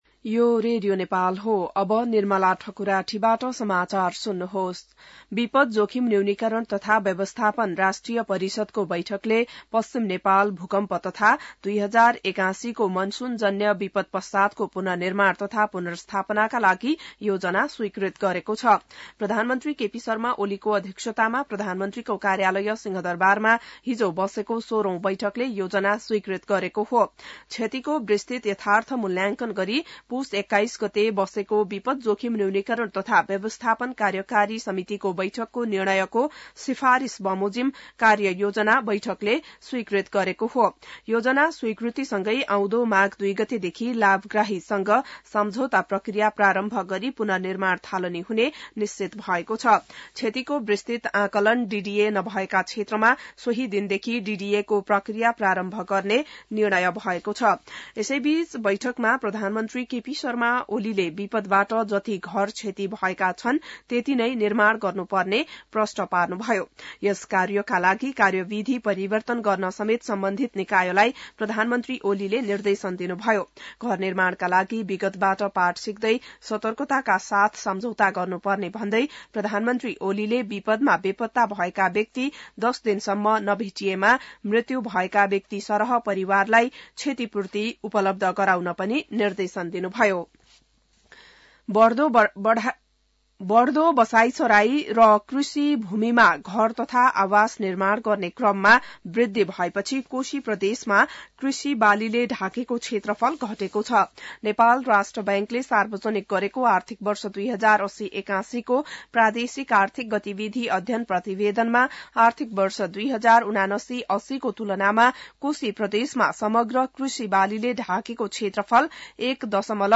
बिहान १० बजेको नेपाली समाचार : १ माघ , २०८१